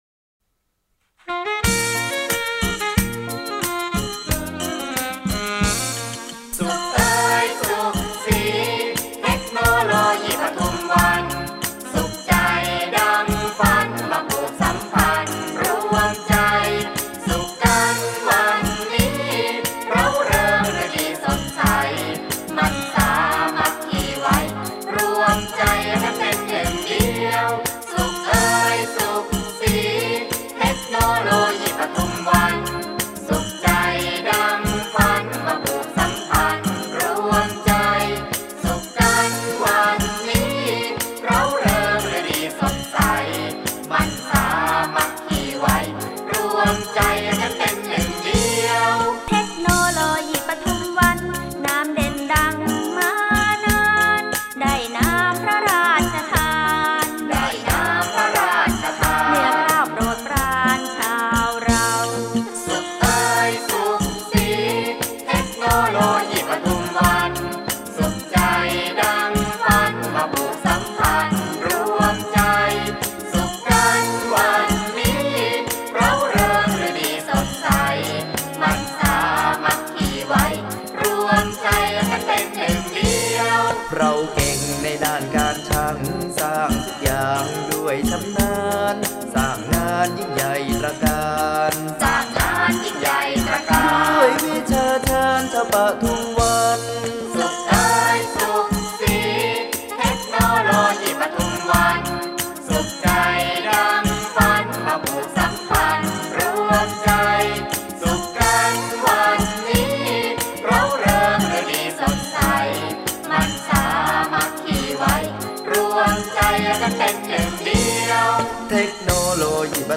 รำวงชาวปทุมวัน (ขับร้องหมู่)